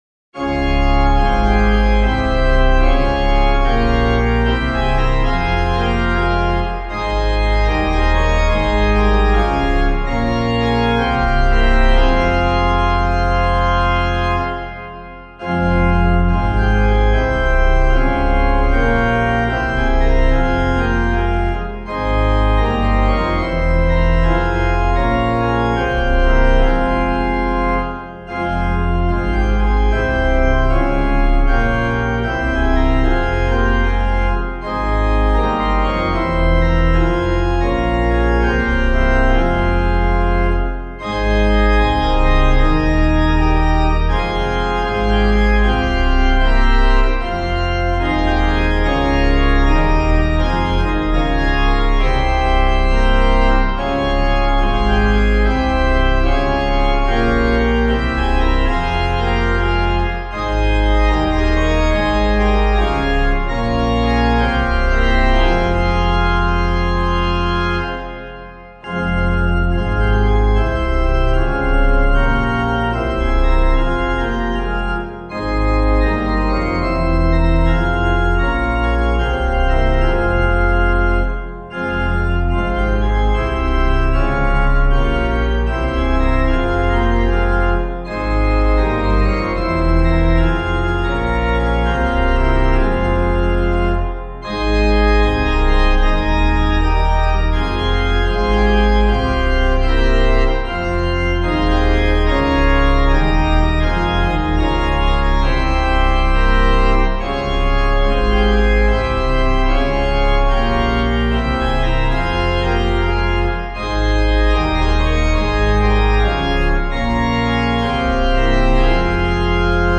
organpiano